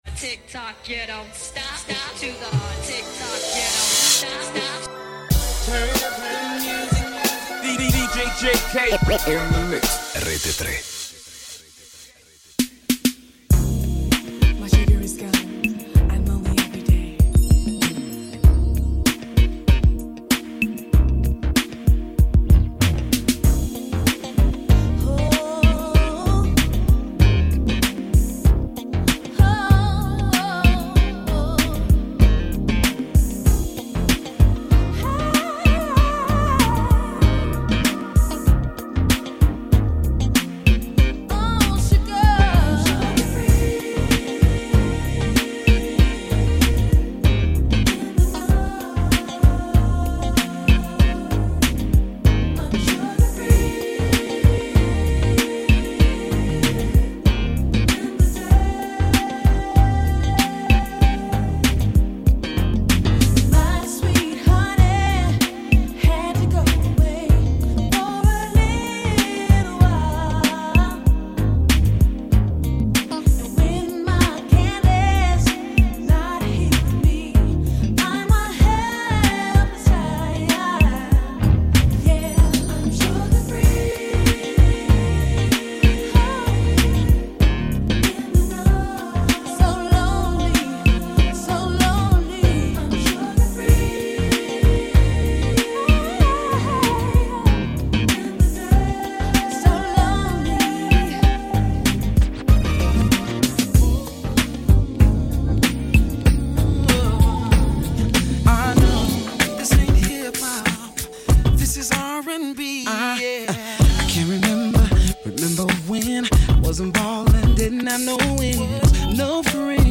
RAP / HIP-HOP